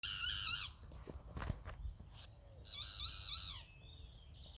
Neinei (Megarynchus pitangua)
Nome em Inglês: Boat-billed Flycatcher
Localidade ou área protegida: Reserva Privada San Sebastián de la Selva
Condição: Selvagem
Certeza: Fotografado, Gravado Vocal